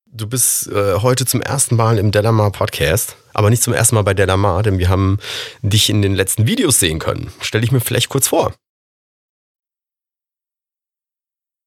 • Die Stimme gleichmäßig laut klingen lassen
Und so klingt es am Ende als “Radiostimme”: